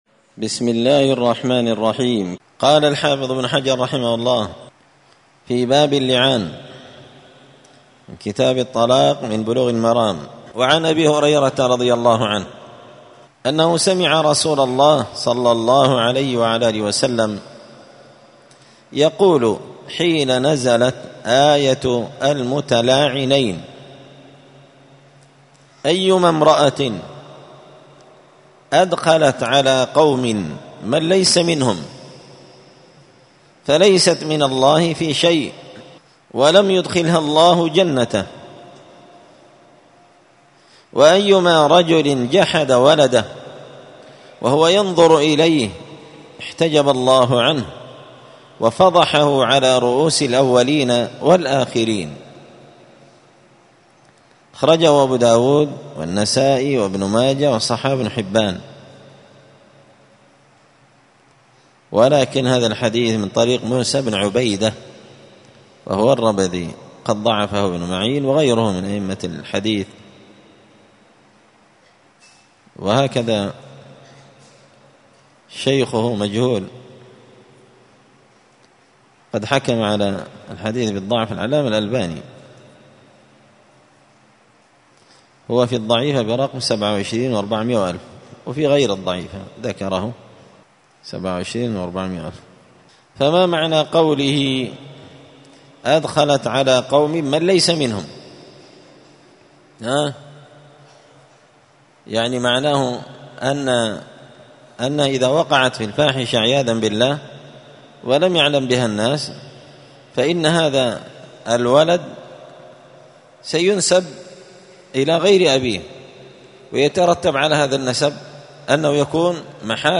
*الدرس السابع عشر (17) {تكملة لباب اللعان وبداية باب العدة الإحداد والاستبراء}*
دار الحديث السلفية بمسجد الفرقان بقشن المهرة اليمن